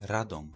Radom - Polish pronunciation